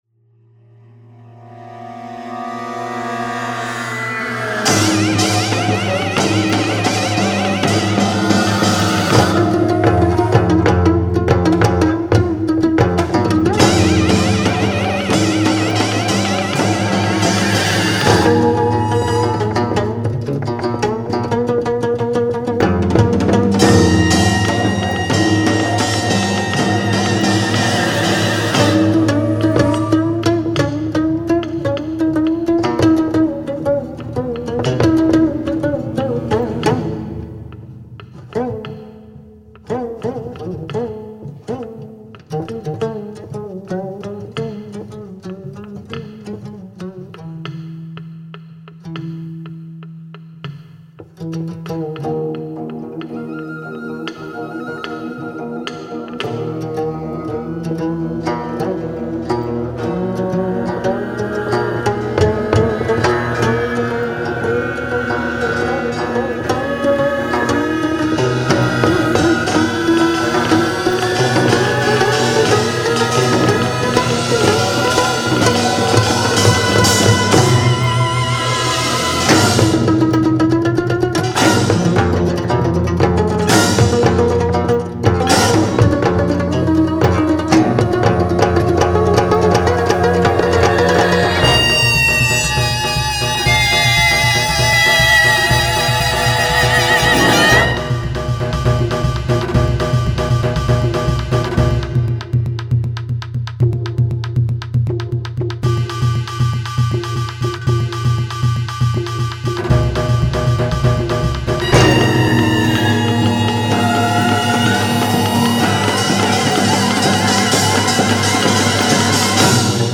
concerto for 9-string geomungo & gugak orchestra